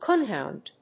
アクセント・音節cóon・hòund